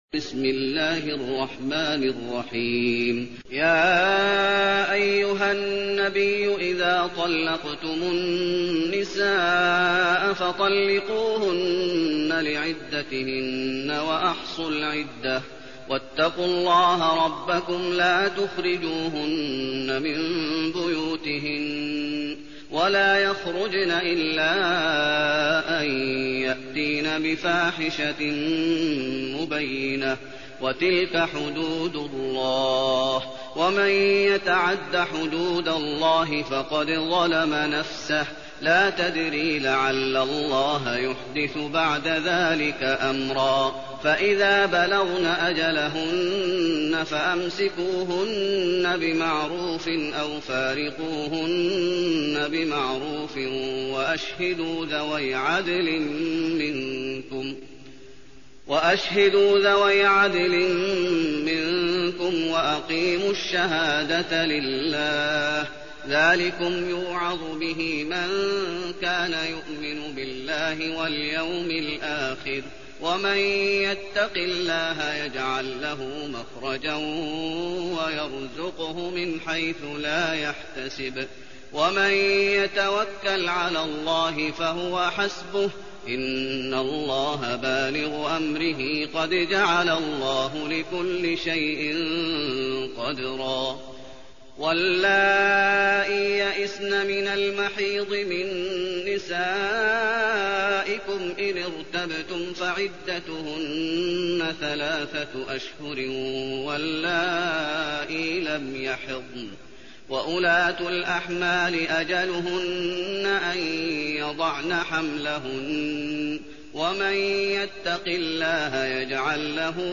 المكان: المسجد النبوي الطلاق